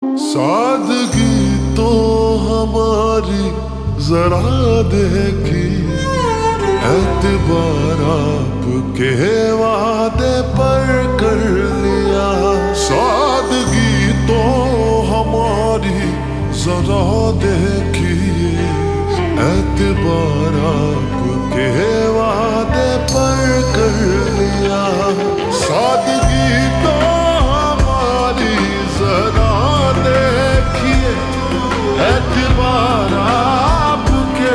Slow Reverb Version
• Simple and Lofi sound
• High-quality audio
• Crisp and clear sound